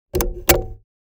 Lawn Mower, Switch On Or Off Sound Effect Download | Gfx Sounds
Lawn-mower-switch-on-or-off.mp3